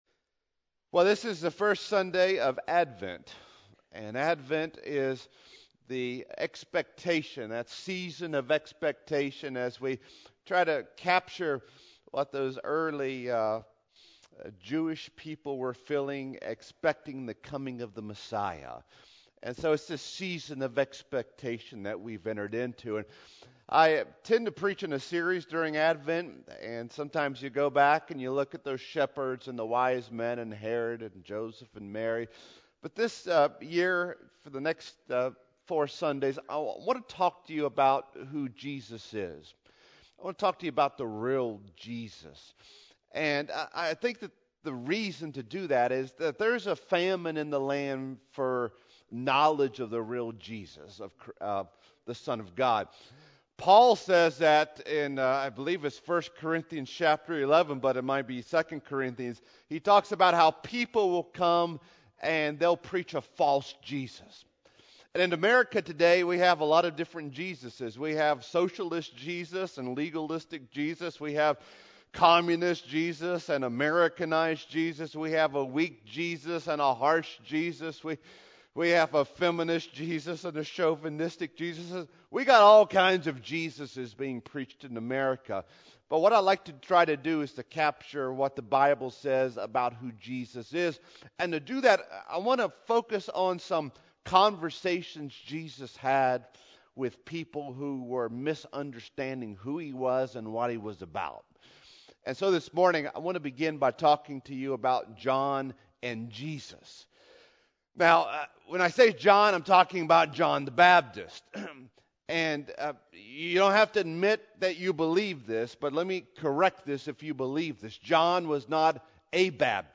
AM Venue: Greeneville First Church of God Service Type: Regular Service Scripture: Matthew 11:1-6